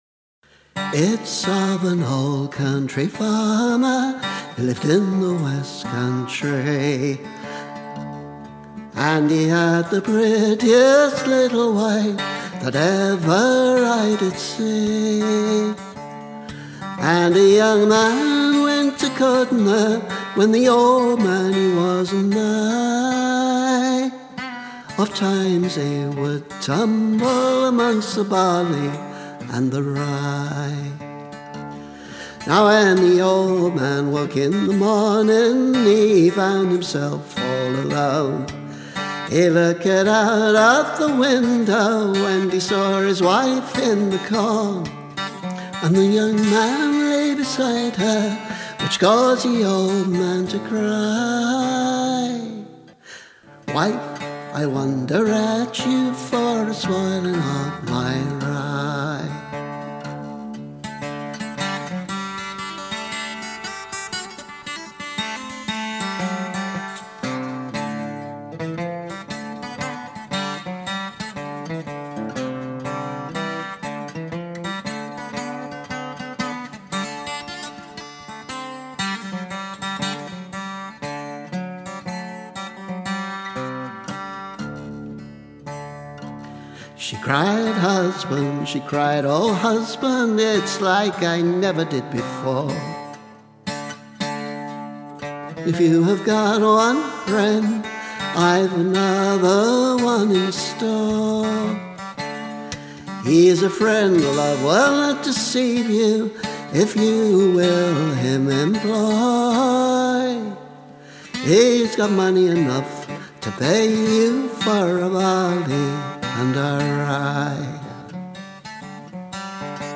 Trying out some new studio gear, I had an unexpectedly folkie moment.
I’ve used a more or less spontaneous acoustic guitar accompaniment here, though.
Ironically, the guitar is somewhat Carthy-esque.